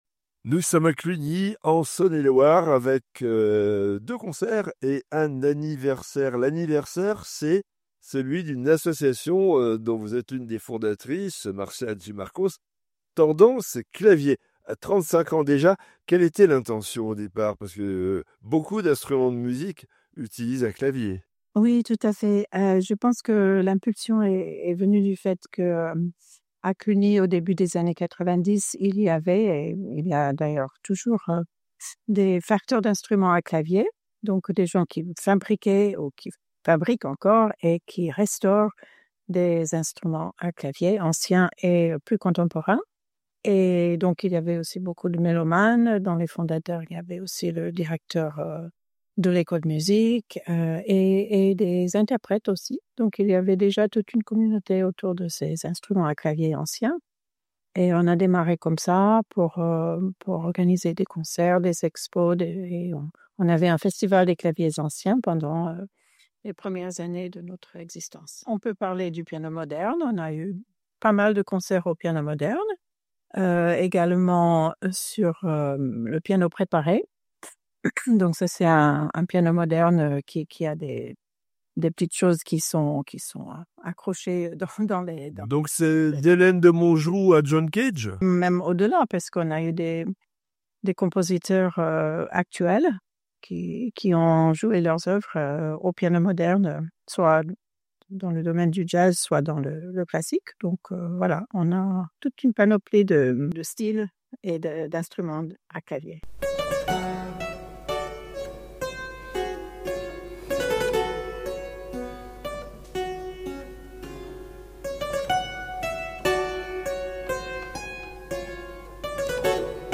a répondu aux questions de BFC Classique